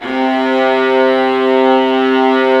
Index of /90_sSampleCDs/Roland LCDP13 String Sections/STR_Violas II/STR_Vas4 Amb f